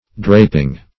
Draping - definition of Draping - synonyms, pronunciation, spelling from Free Dictionary